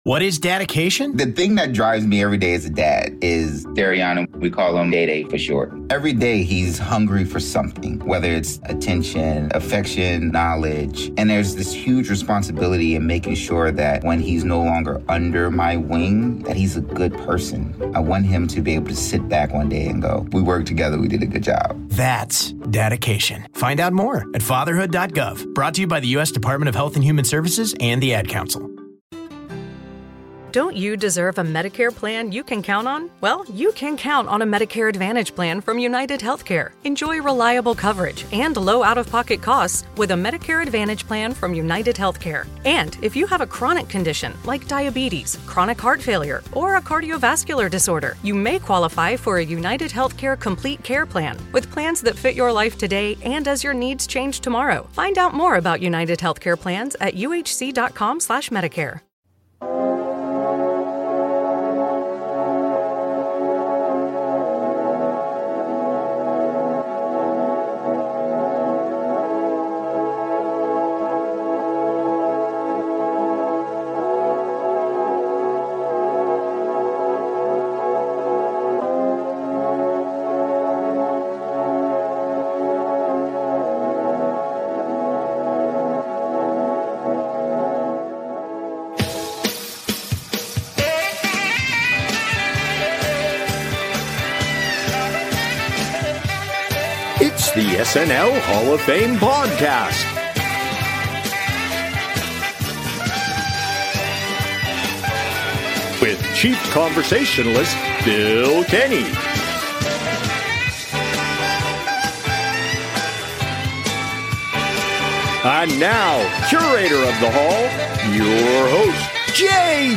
It’s a retrospective filled with gratitude, laughs, and some surprising moments of reflection.